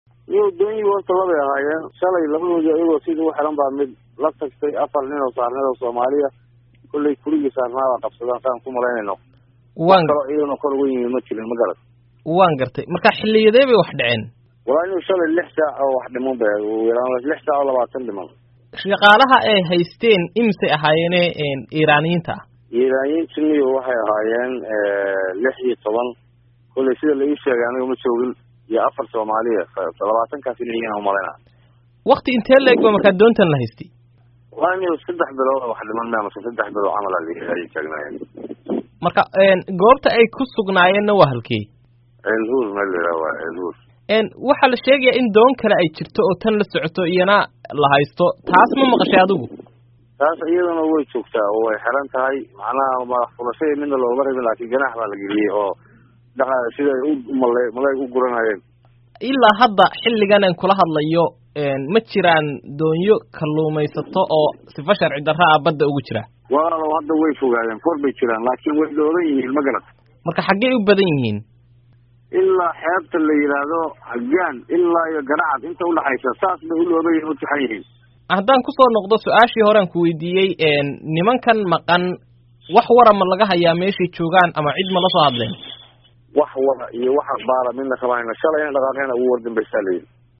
Wareysi: Dooni Baxsatay